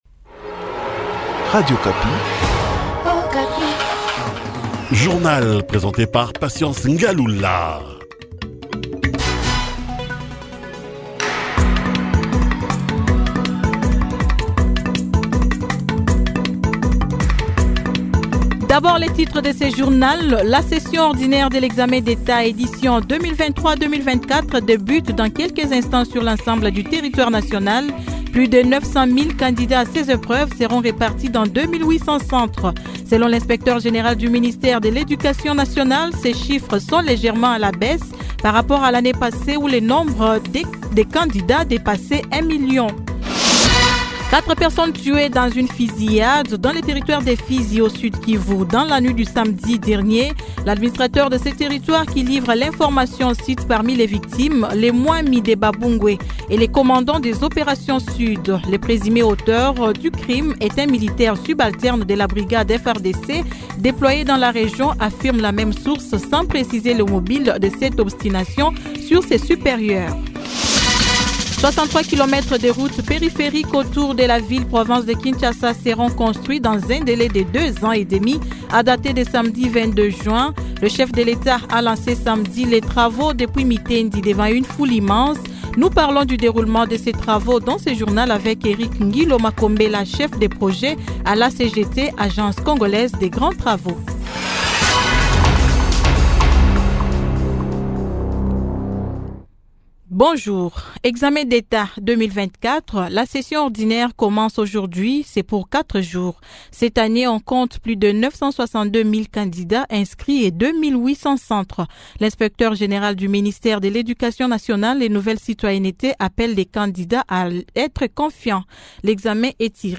Journal matin 08H
Kinshasa : reportage lancement des travaux des rocades.